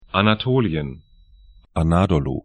Anatolien ana'to:lĭən Anadolu a'na:dɔlu tr Gebiet / region 38°30'N, 33°10'E